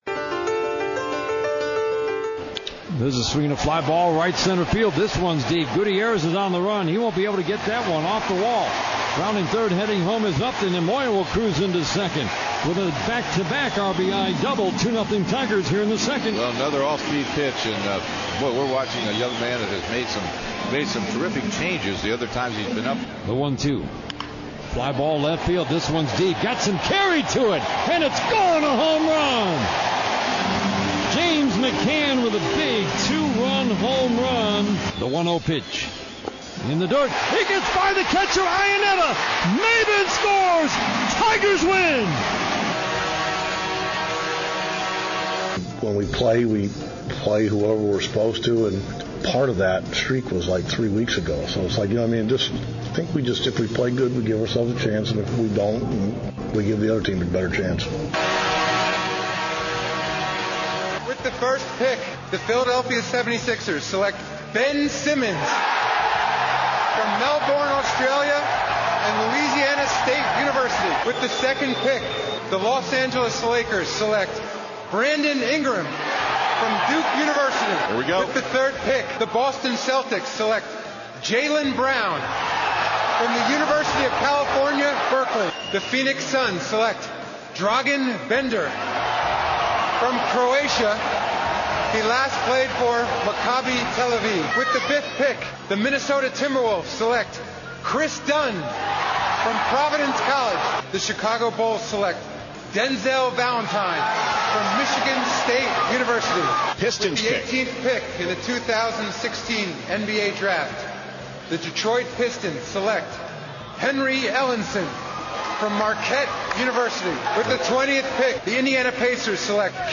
a round table of beat writers.